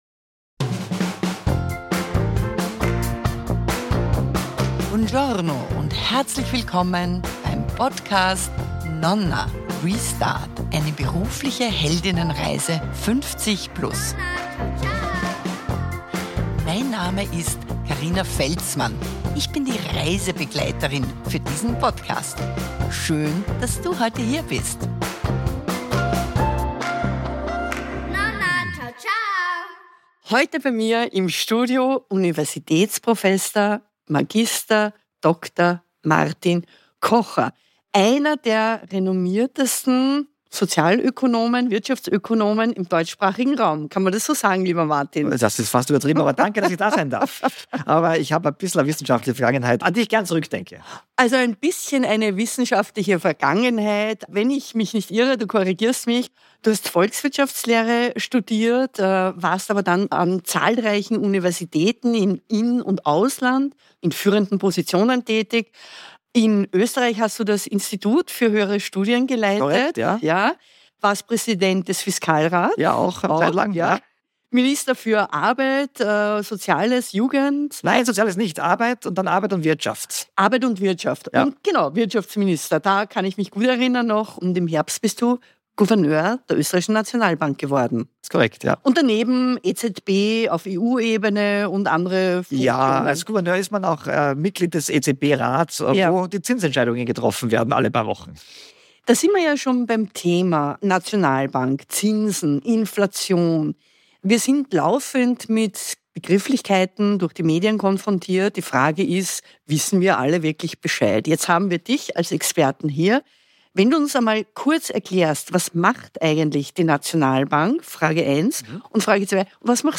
Beschreibung vor 6 Tagen In diesem Fachinterview spreche ich mit Gouverneur Martin Kocher über die wirtschaftliche Großwetterlage.